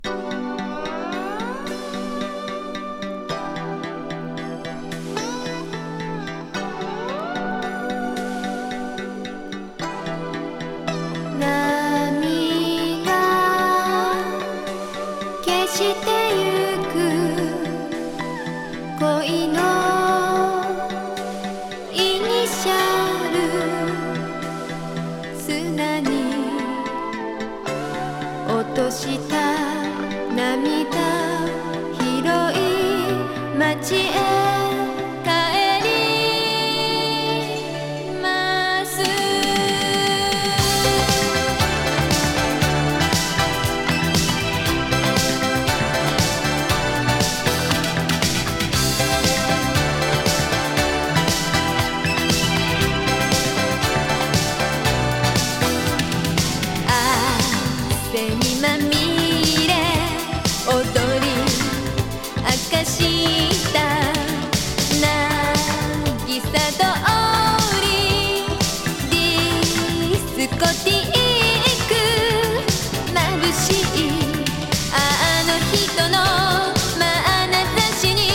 少したどたどしいヴォーカルが魅力！